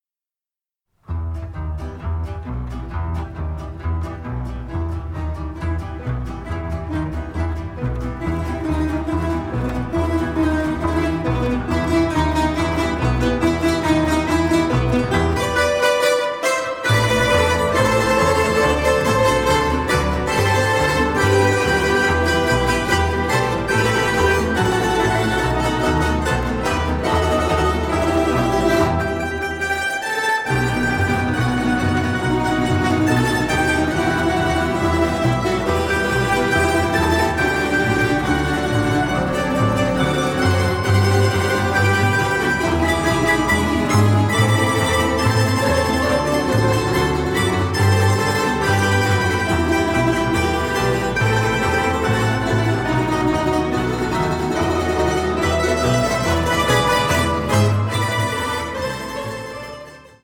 Tamburizza ensemble · Tamburaška skupina
ruska ciganska